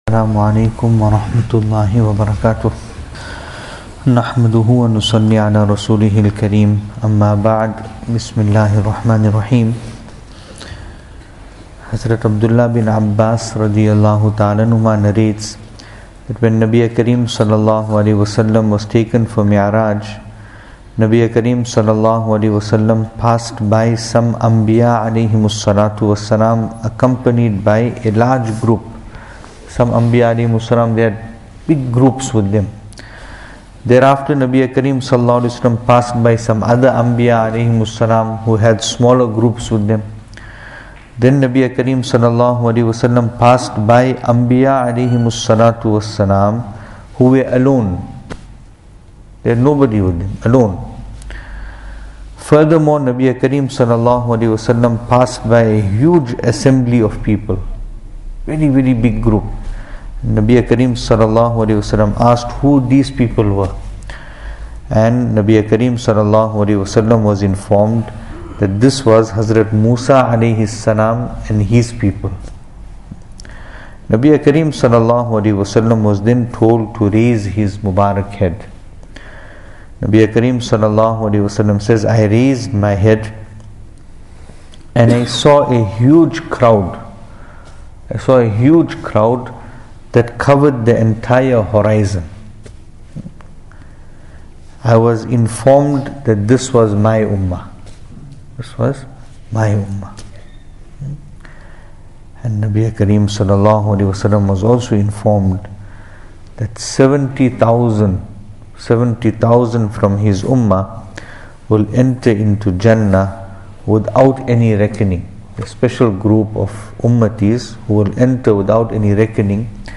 Venue: Masjid Taqwa, Pietermaritzburg | Series: Seerah Of Nabi (S.A.W)
Service Type: Majlis